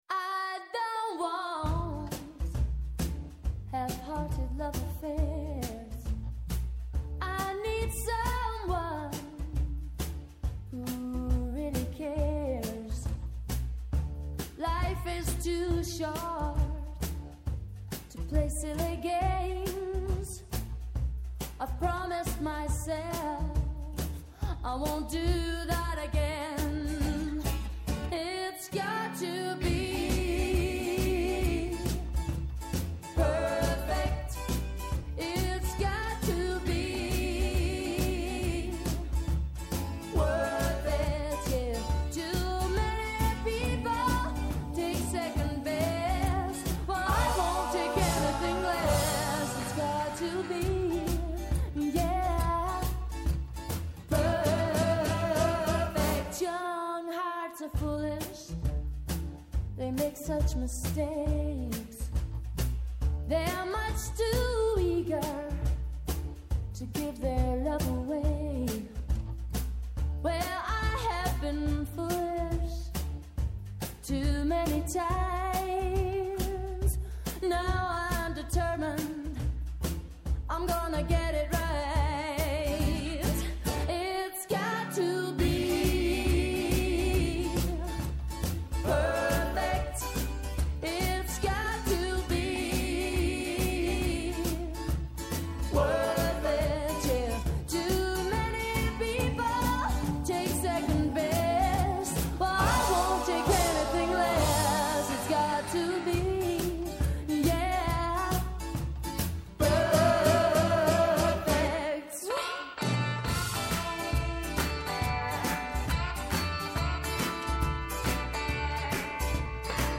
Κάθε Παρασκευή 10 με 11 το πρωί και κάθε Σάββατο 10 το πρωί με 12 το μεσημέρι στο Πρώτο Πρόγραμμα της Ελληνικής Ραδιοφωνίας.